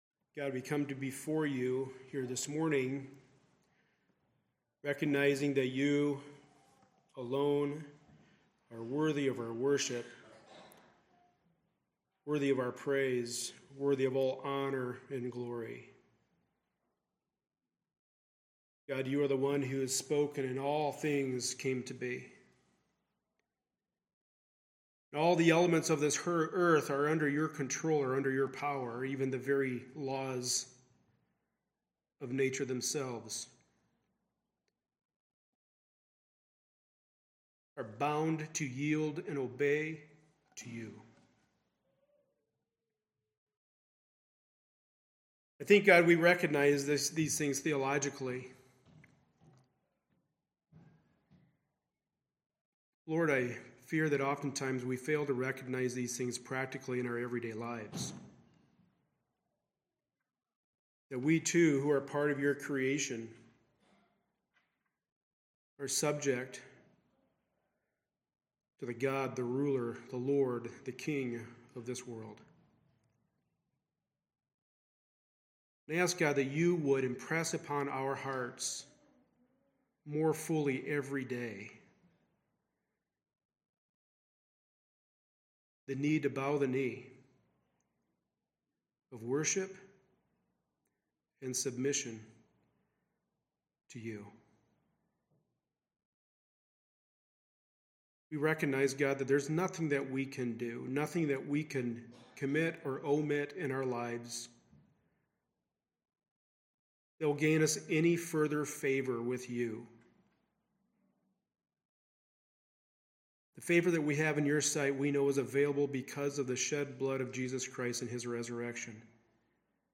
Passage: Titus 2:1-10 Service Type: Sunday Morning Service